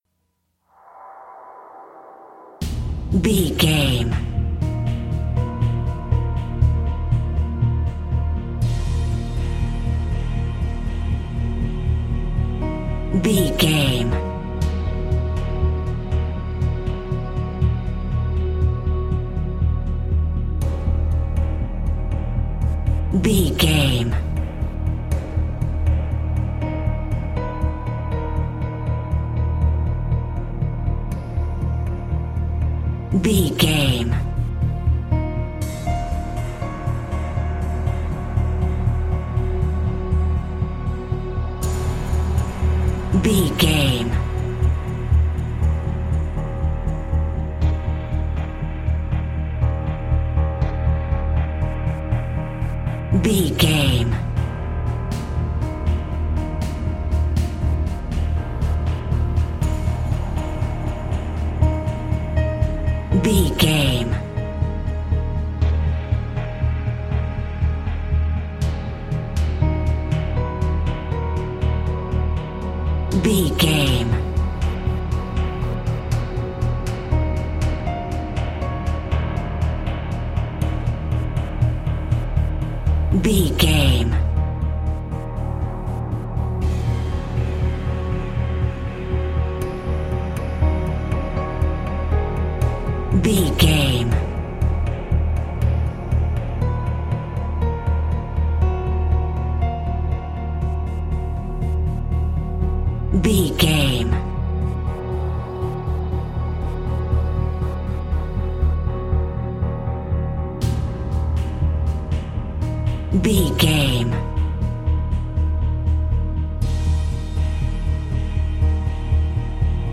Thriller
Ionian/Major
E♭
synthesiser
percussion
piano
tension
ominous
dark
mysterious
haunting
creepy